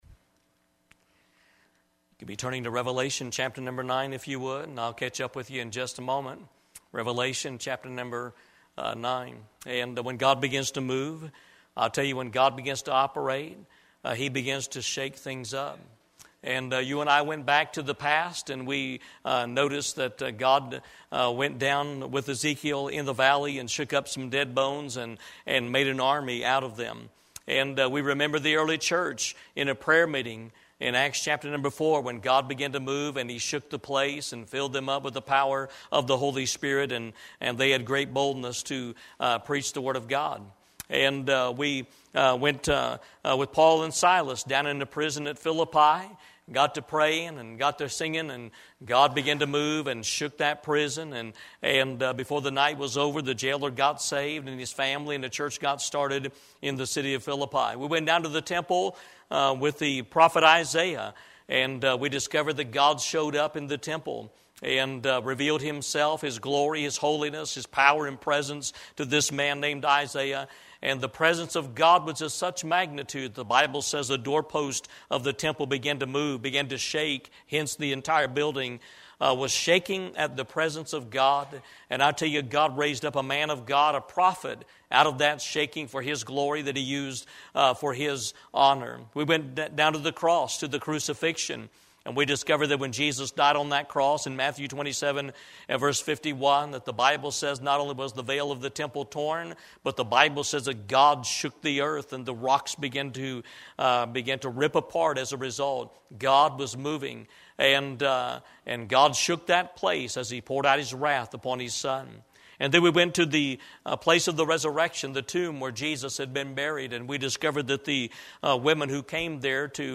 Crammed pack with Biblical truth and prophecy, tonight's message motivates us to seek and serve God as we see our culture rapidly declining.